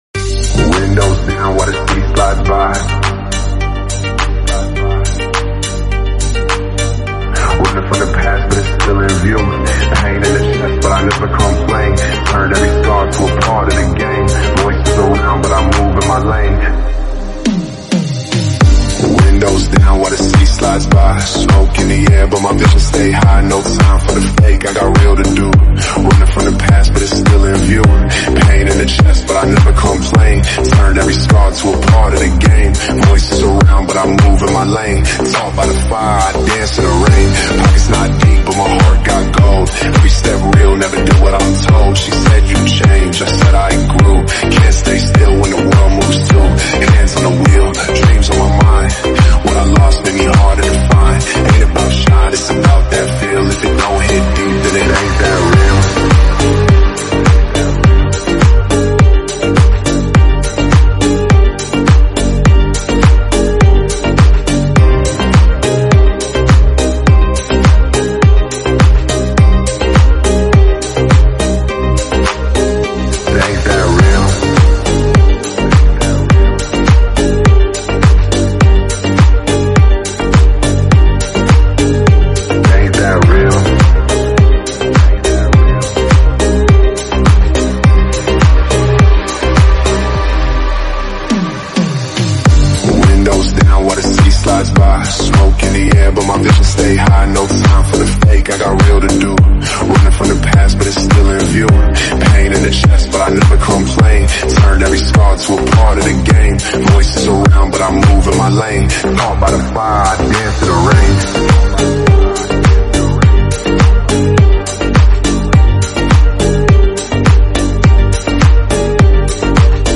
Ford Mustang Teil 2 sound effects free download
2 Mp3 Sound Effect Ford Mustang Teil - 2 Soundcheck 💪!!!!!!!